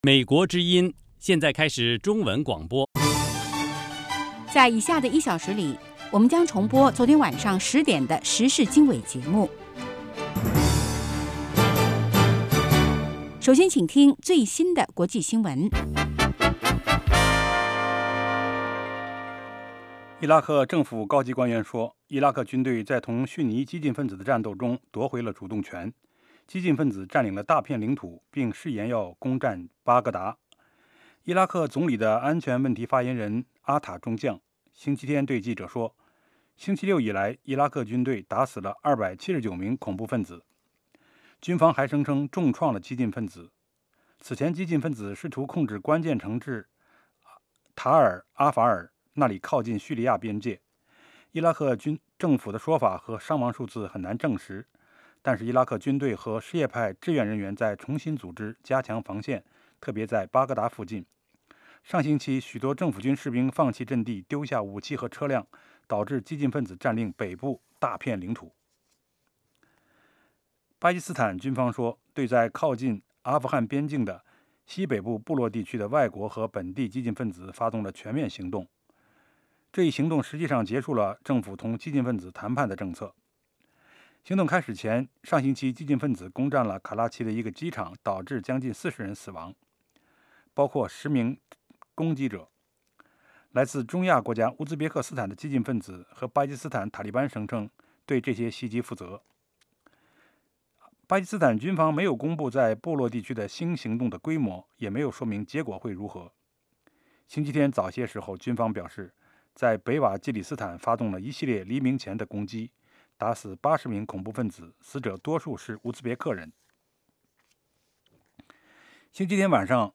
国际新闻 时事经纬(重播) 北京时间: 上午6点 格林威治标准时间: 2200 节目长度 : 60 收听: mp3